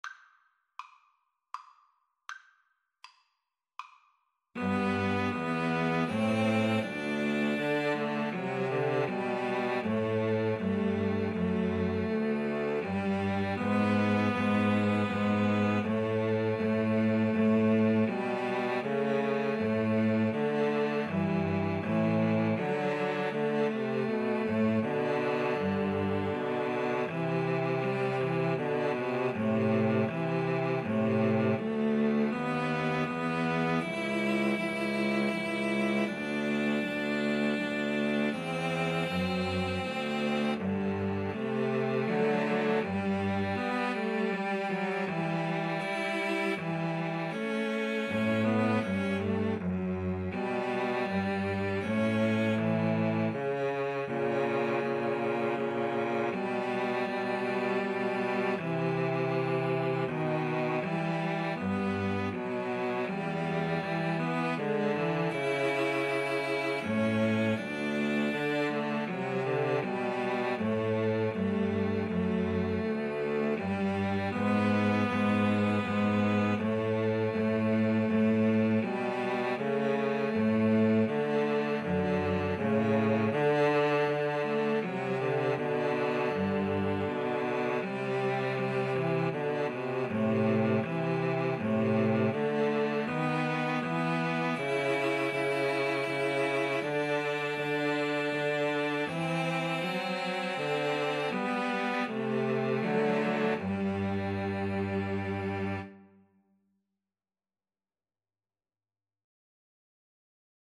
Andante
Classical (View more Classical String trio Music)